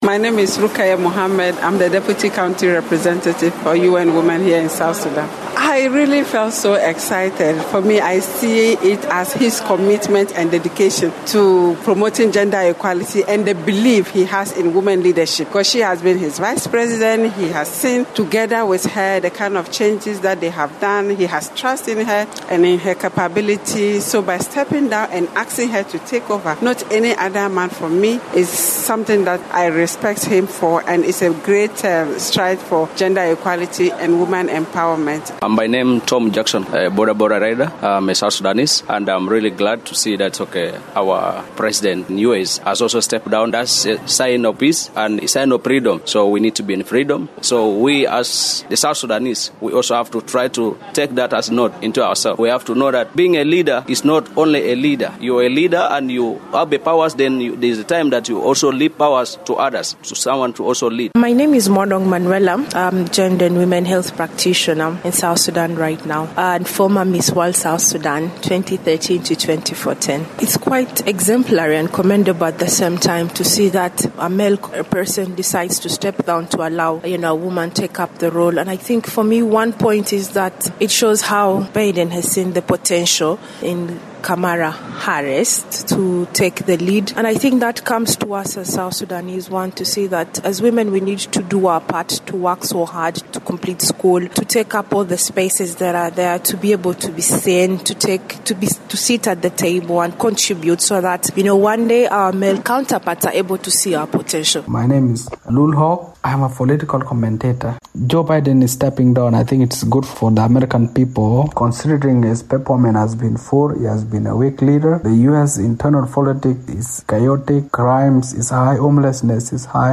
went to streets of Juba to gauge the mood of residents on US politics.